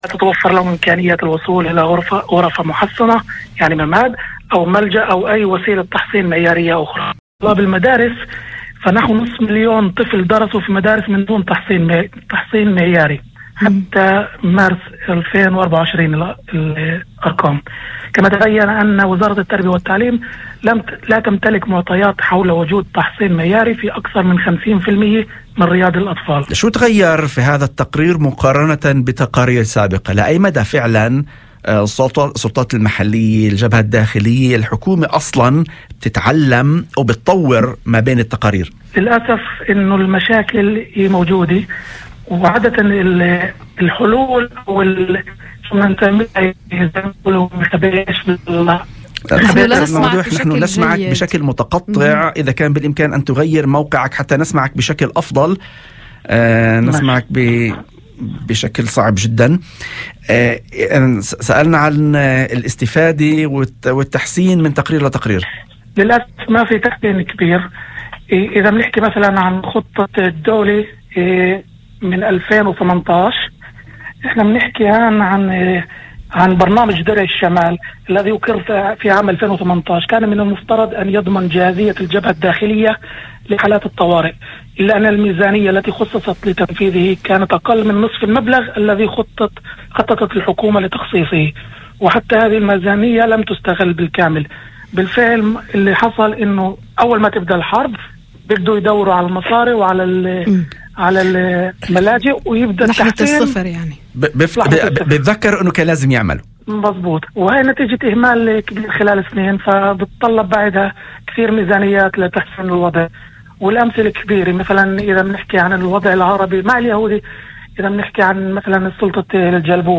في مداخلة لإذاعة الشمس